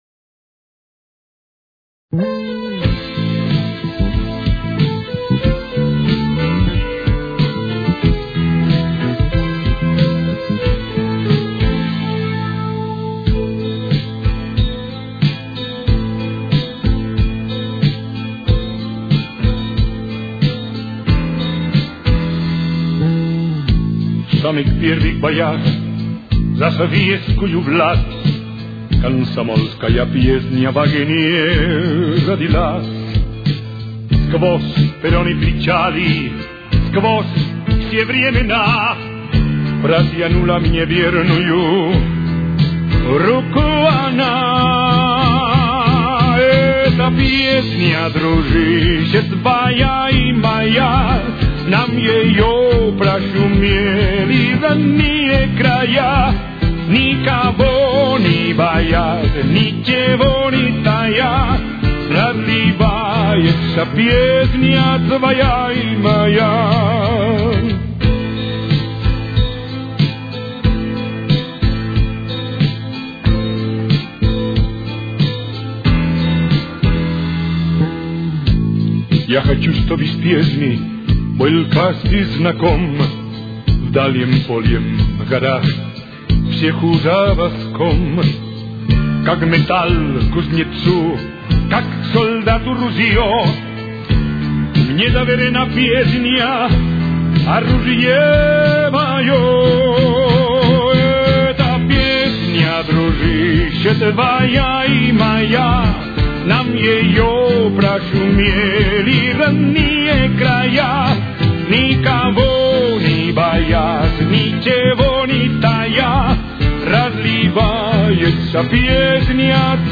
Темп: 94.